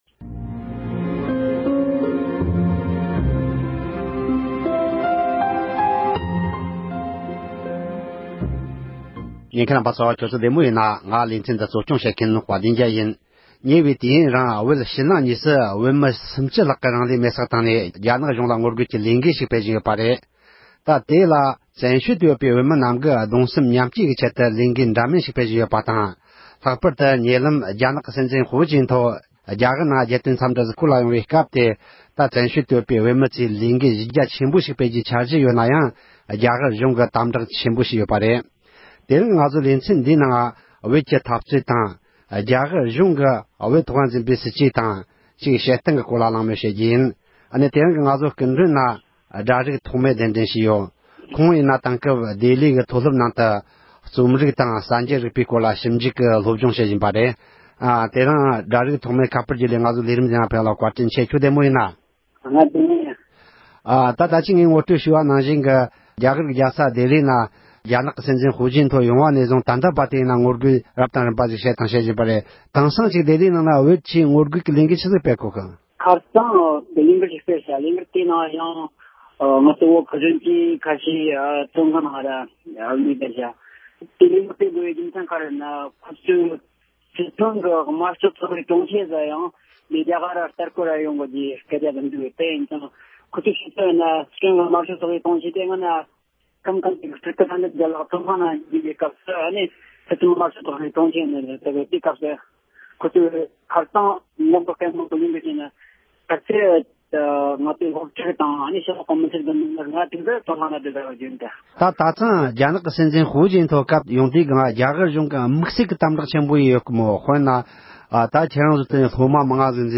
བོད་ཀྱི་འཐབ་རྩོད་དང་རྒྱ་གར་གྱིས་བོད་ཐོག་འཛིན་པའི་སྲིད་ཇུས་ཐད་འབྲེལ་ཡོད་མི་སྣ་དང་གླེང་མོལ་ཞུས་པ།